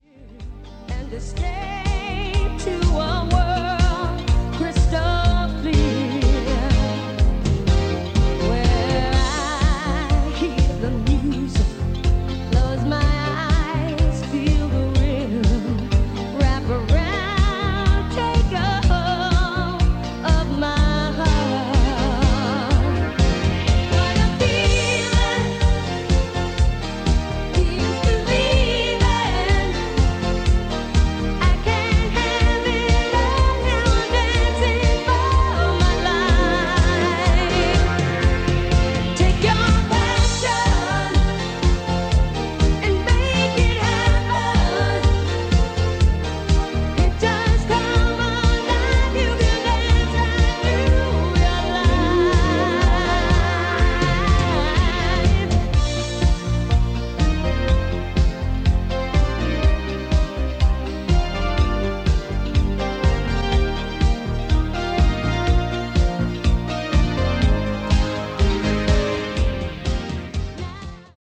Below is a test recording makde with the 112R and played back by it:
TASCAM-112R-Test-Recording.mp3